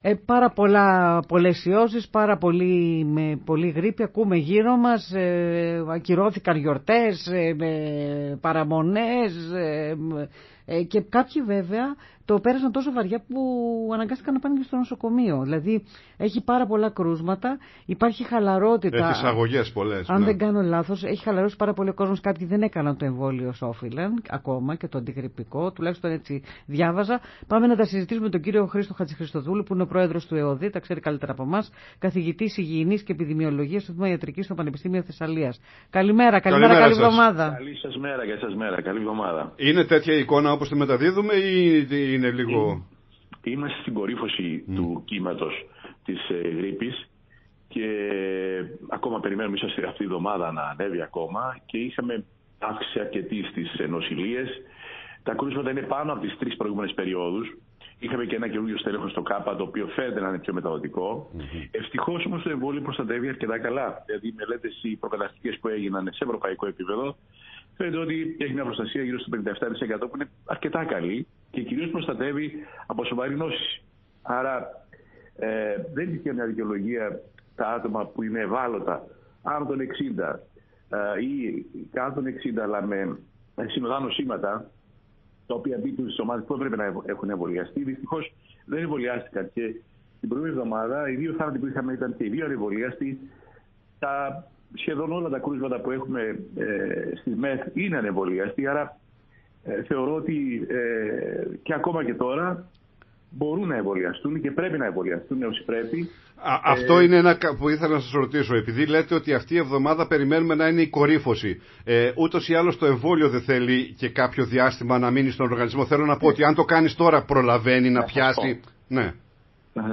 Χρήστος Χατζηχριστοδούλου, Πρόεδρος ΕΟΔΥ και Καθηγητής Υγιεινής και Επιδημιολογίας στο Τμήμα Ιατρικής στο Πανεπιστήμιο Θεσσαλίας, μίλησε στην εκπομπή Πρωινή Παρέα